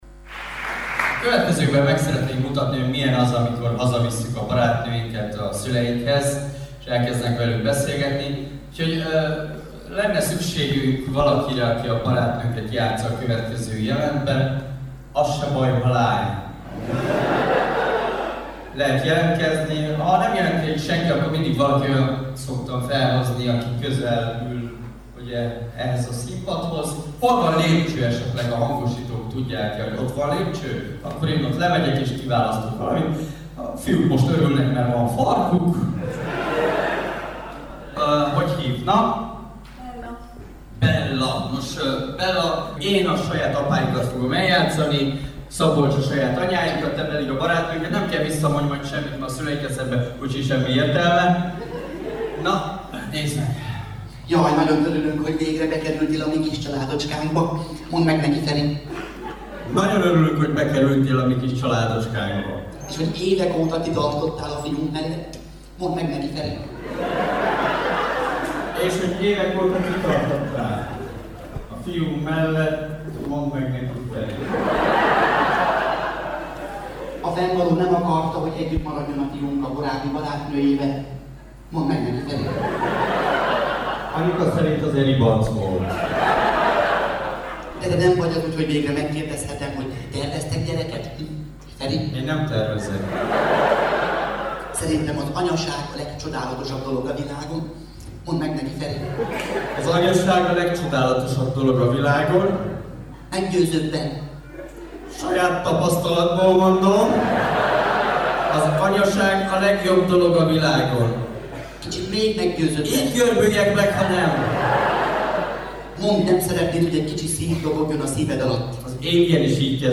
A szenzációszámba menő leleplezés azonban semmit sem von le a humoristák érdemeiből, hiszen a közönség remekül szórakozott.